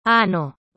• Le « Ñ » : Ce n qui est surmonté d’une vaguelette (que l’on appelle un tilde) se prononce [eñe] comme dans le mot français gagner ou dans le terme espagnol « año » (année).